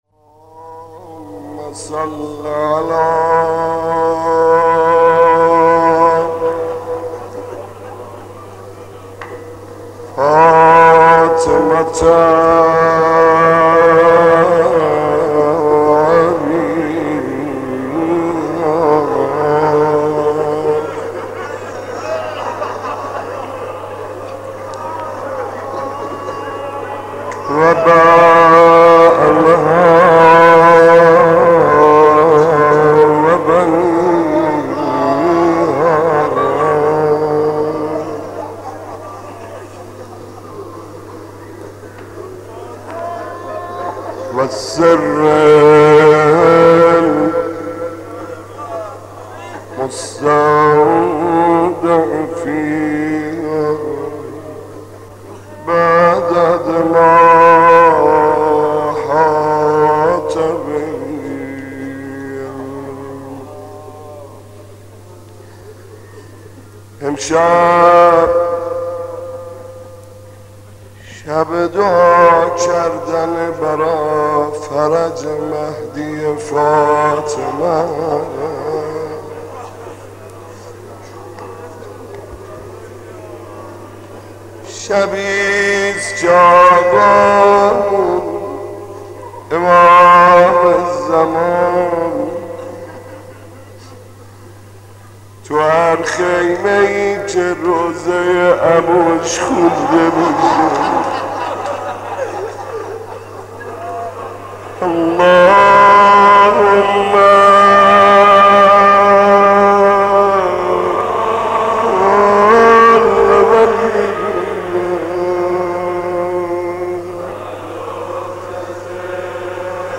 مداح
مناسبت : تاسوعای حسینی